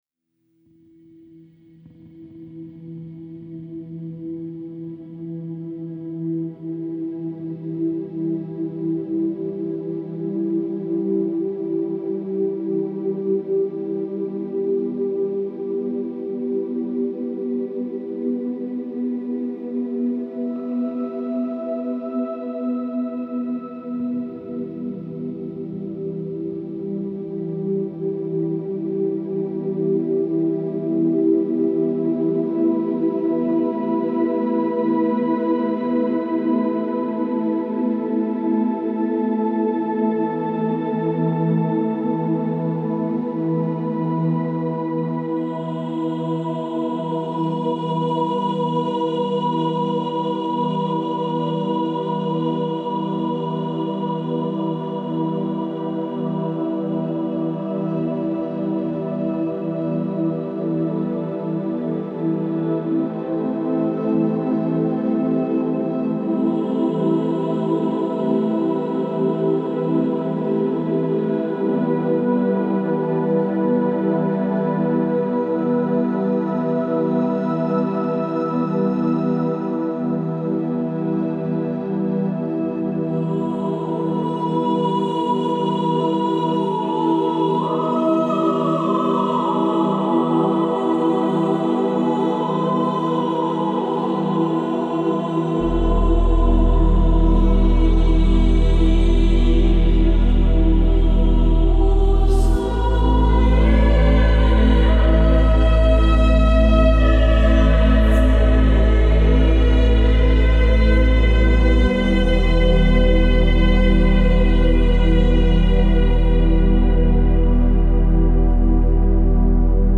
سوپرانو